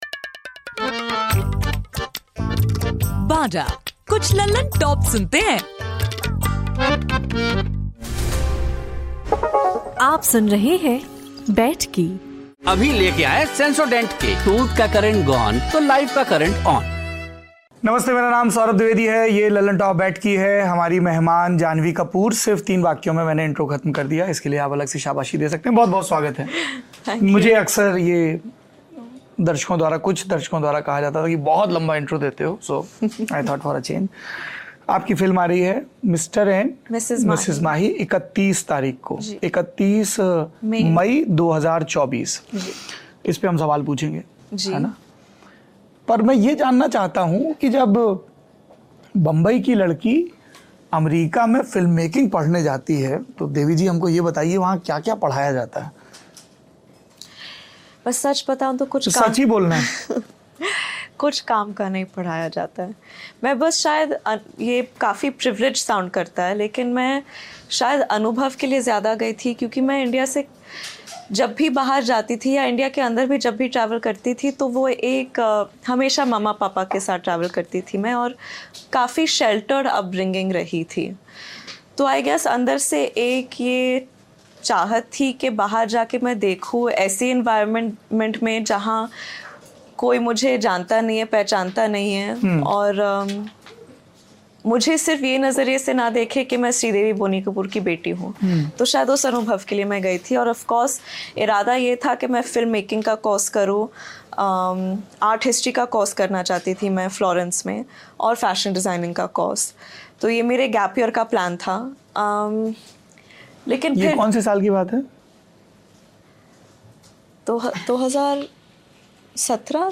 गेस्ट इन द न्यूज़रूम के इस पॉडकास्ट में सुनिए दी लल्लनटॉप के सौरभ द्विवेदी की बातचीत डायरेक्टर, प्रोडूसर और स्क्रीनराइटर इम्तियाज अली के साथ. इम्तियाज़ अली भारतीय फिल्म इंडस्ट्री के जाने माने डायरेक्टर्स में से एक हैं.